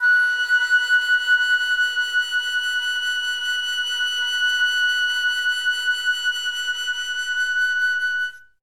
51c-flt11-F#5.wav